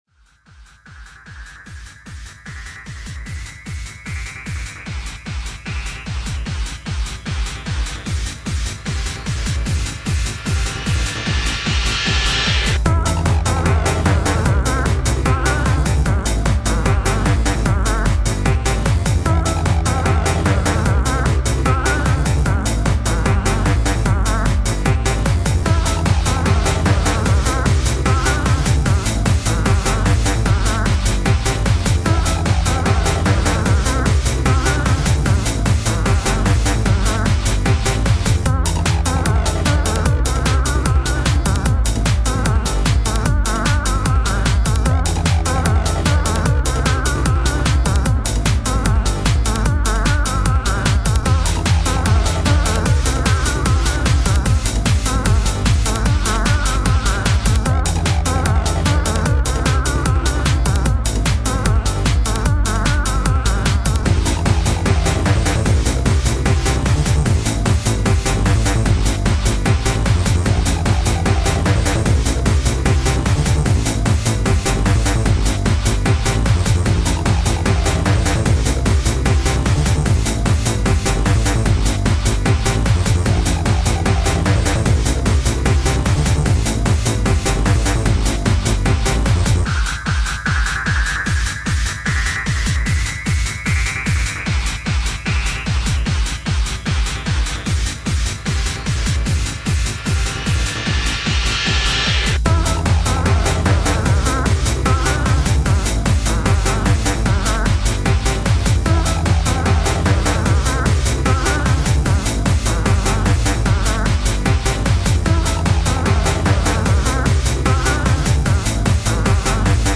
Very dancey techno song with a fun frog synth lead
Definitely not a sad song.